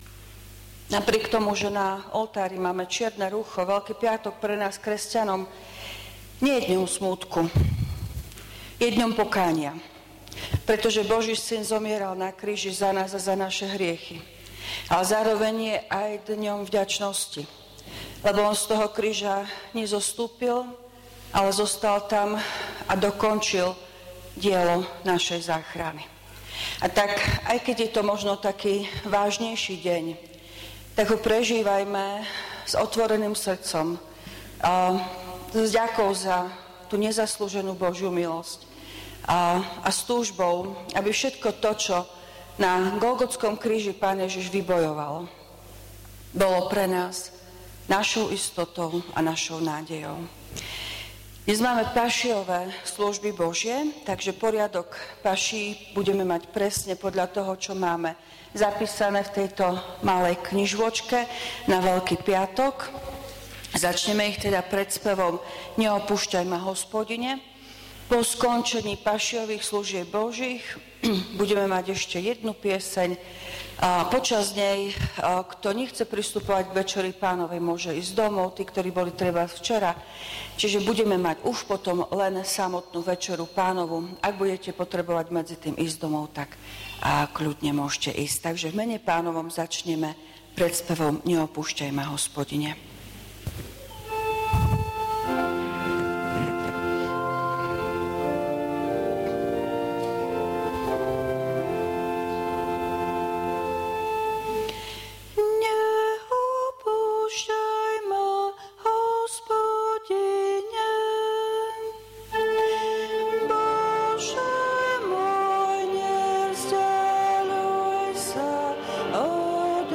Služby Božie – Veľký piatok
V nasledovnom článku si môžete vypočuť zvukový záznam zo služieb Božích – Veľký piatok.